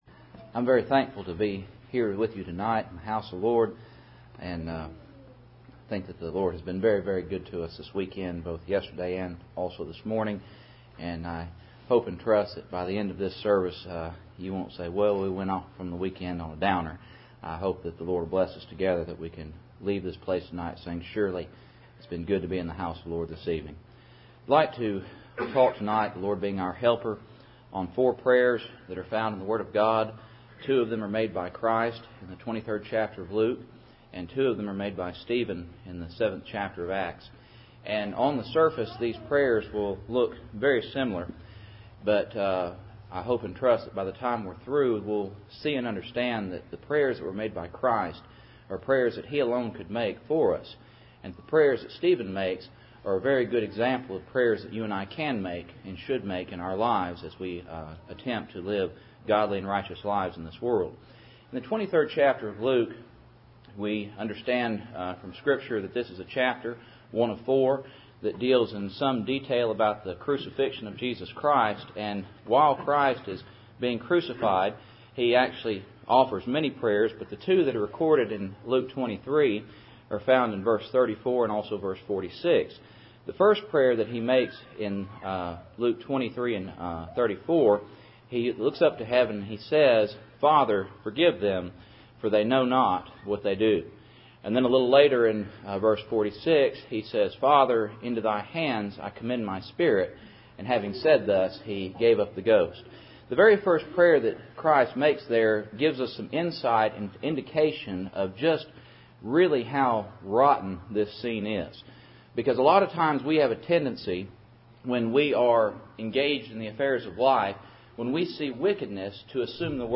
Acts 7:59-60 Service Type: Cool Springs PBC Sunday Evening %todo_render% « Part 2